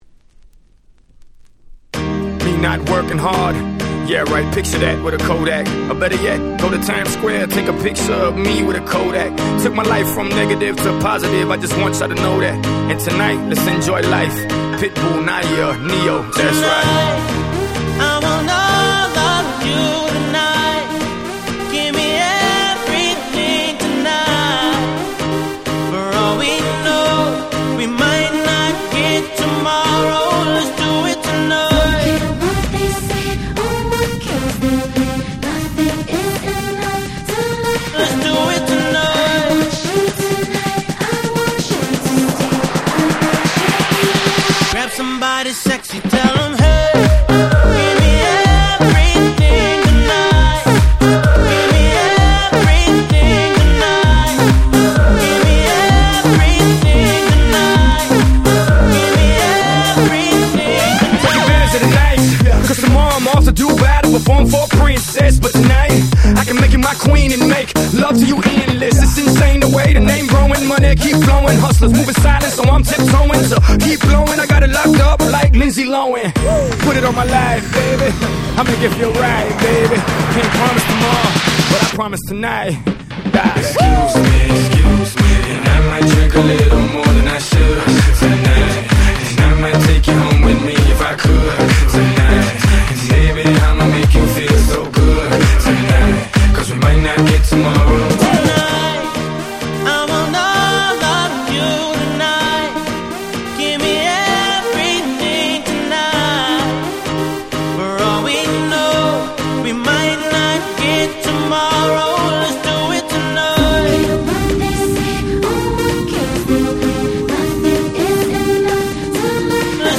11' Super Hit R&B / Pops !!
R&B キャッチー系